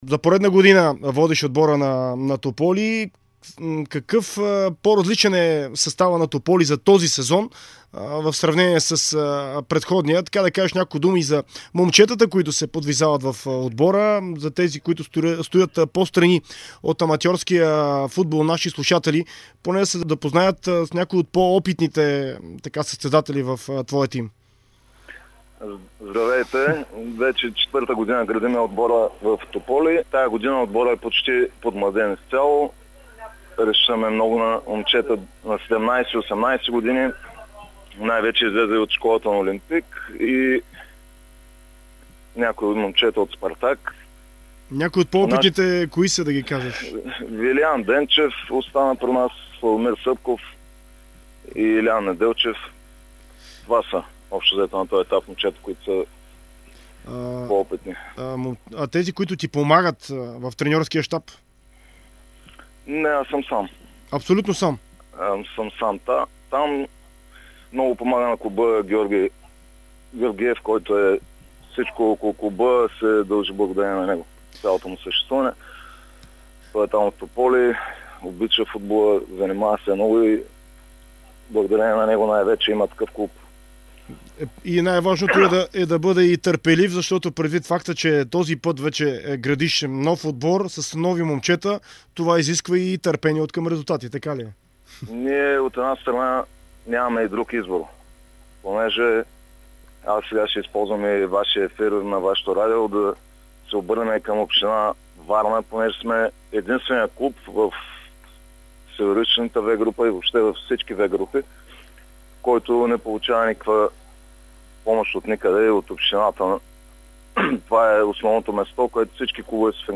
В интервю за Дарик радио и dsport той говори за целите пред тима и проблемите, които го съпътстват.